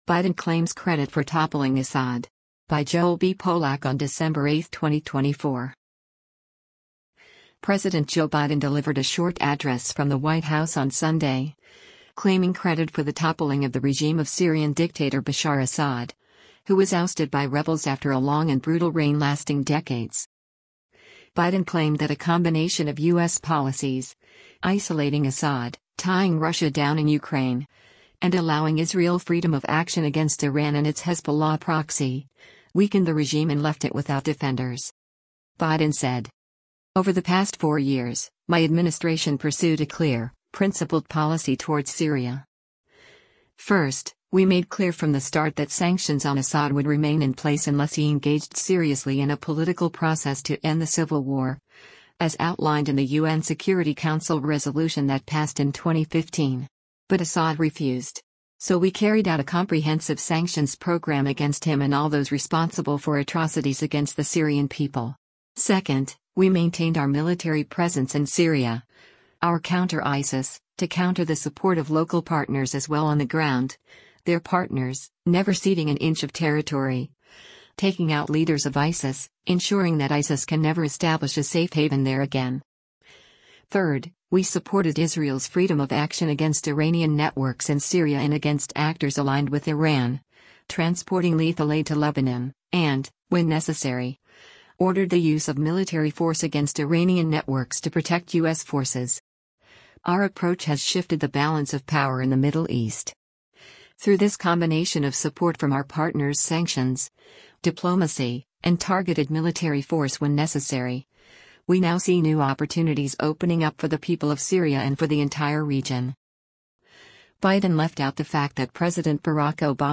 President Joe Biden delivered a short address from the White House on Sunday, claiming credit for the toppling of the regime of Syrian dictator Bashar Assad, who was ousted by rebels after a long and brutal reign lasting decades.